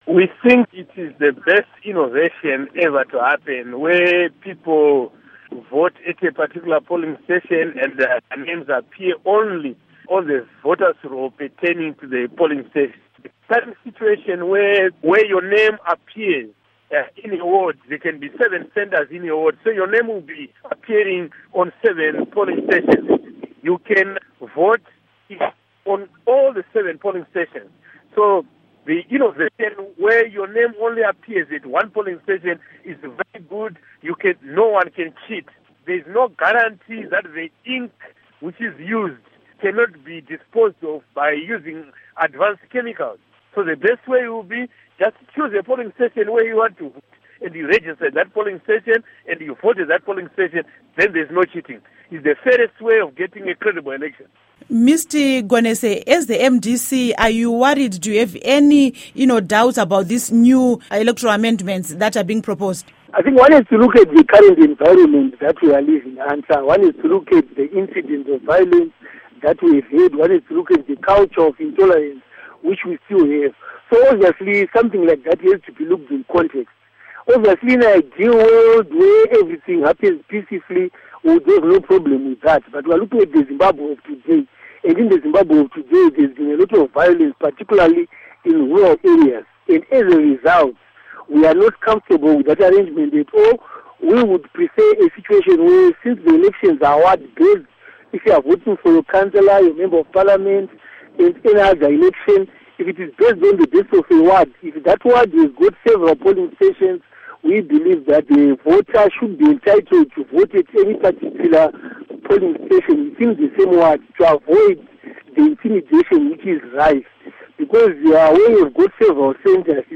Interview With Innocent Gonese & Paul Munyaradzi Mangwana